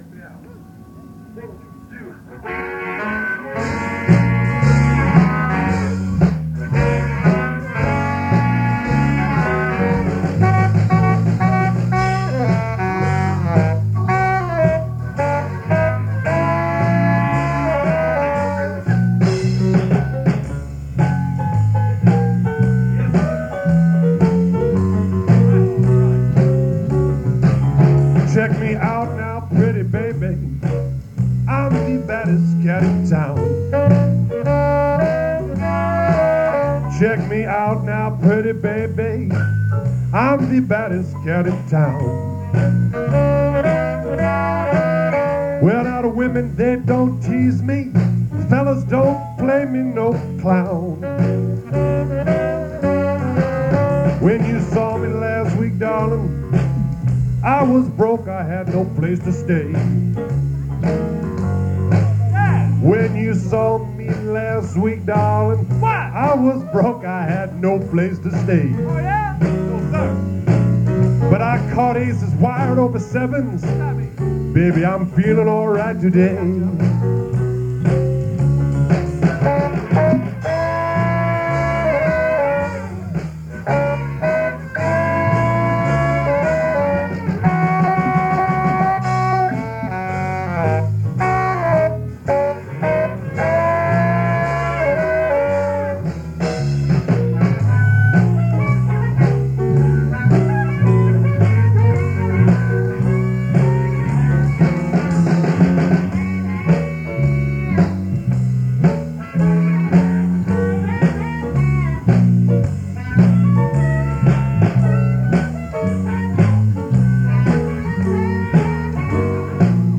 Guitar & Lead Vocals
Drums
Saxophones
- Piano & Organ